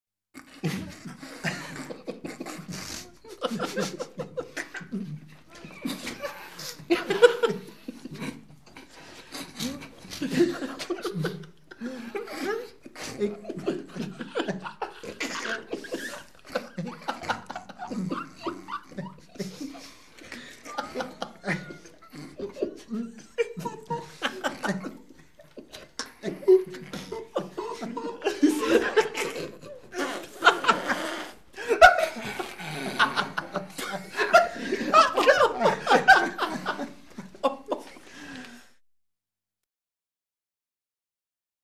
众人憋笑挑战音效_人物音效音效配乐_免费素材下载_提案神器